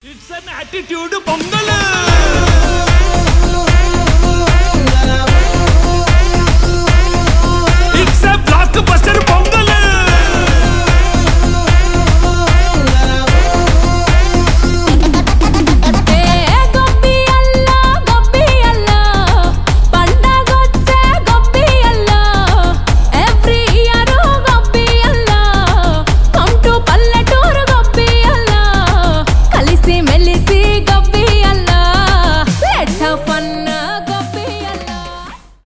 индийские, танцевальные
зажигательные